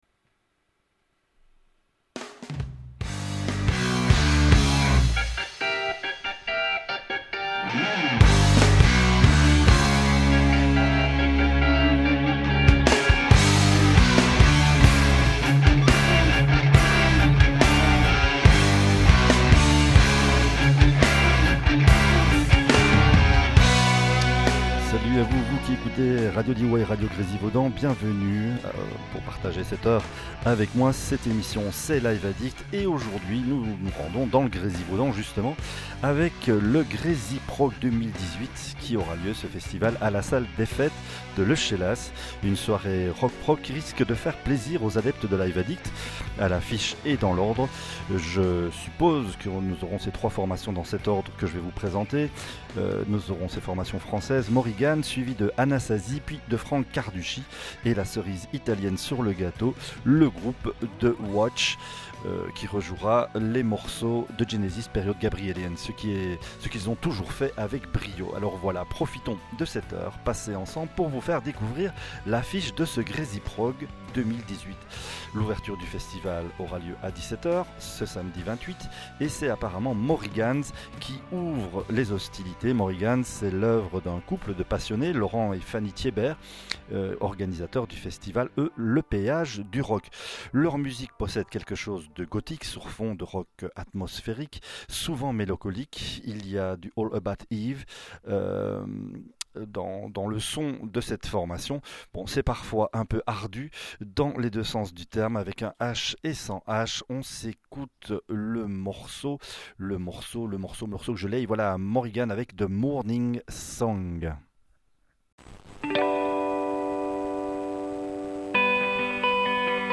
rock progressif